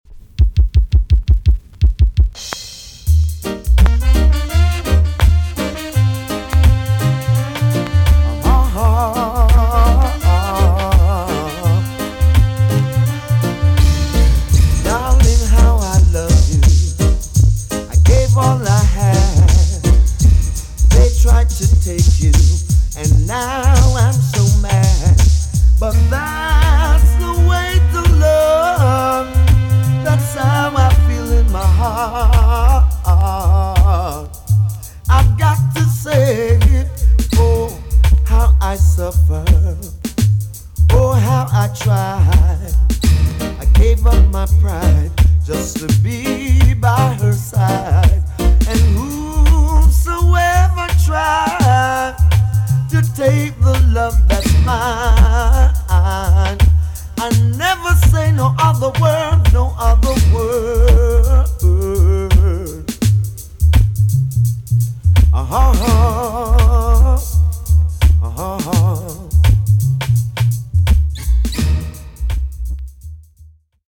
TOP >DISCO45 >80'S 90'S DANCEHALL
HOUSE MIX
EX-~VG+ 少し軽いプチノイズが入りますが良好です。